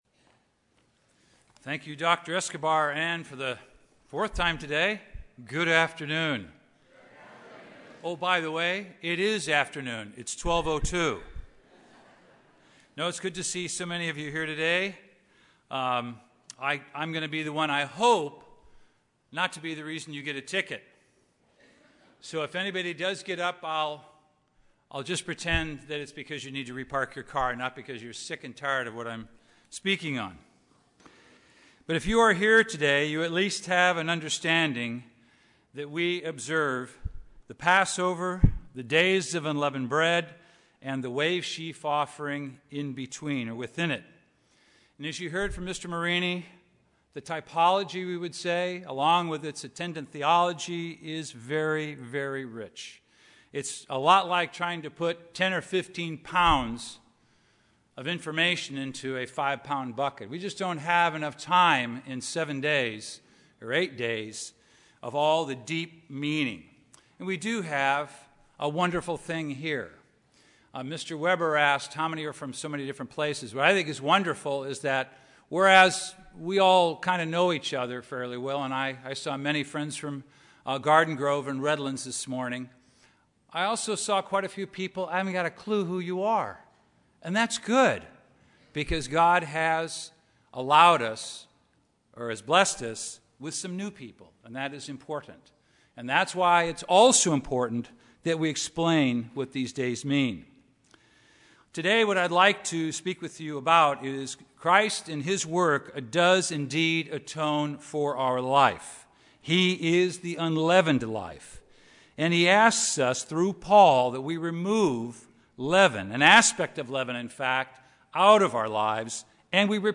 Using the Days of Unleavened Bread and the Parable of the Good Samaritan as a backdrop, this sermon analyzes the importance of expressing humility, service, compassion and mercy in our thoughts and actions.